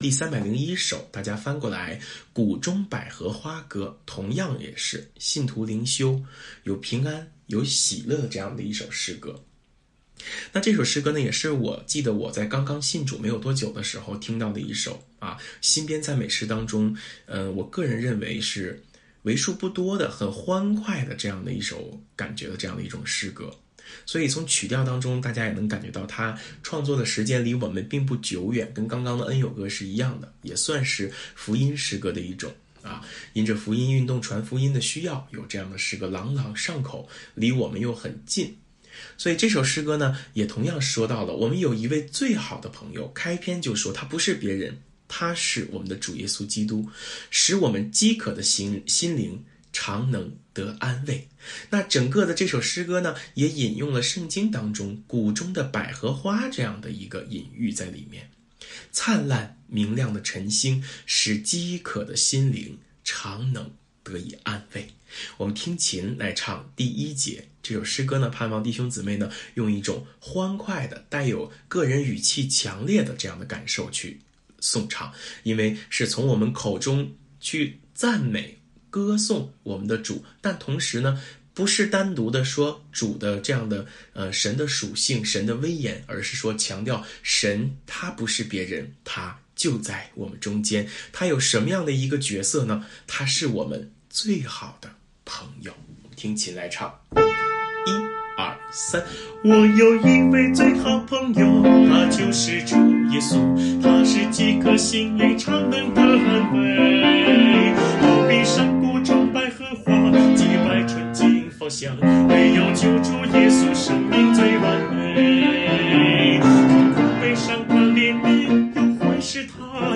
【颂唱练习】
温馨提示：每次礼拜前十五分钟进行崇拜预习和诗歌颂唱学习。